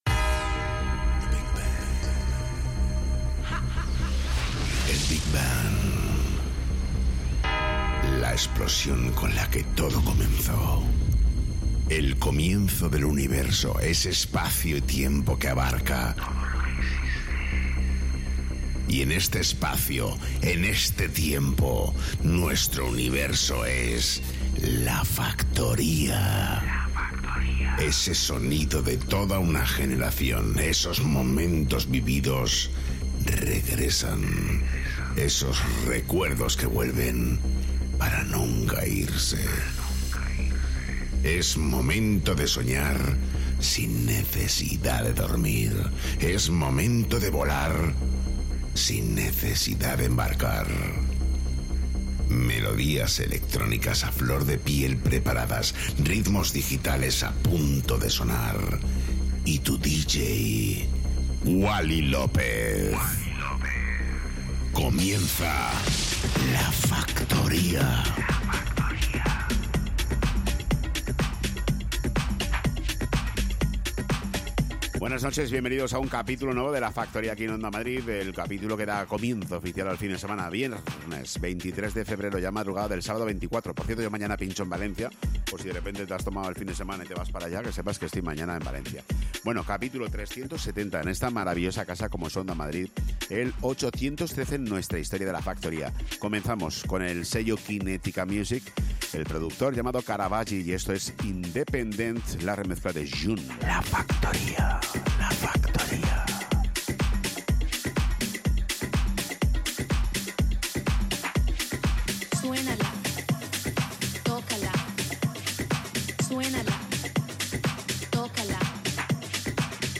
el DJ más internacional de Madrid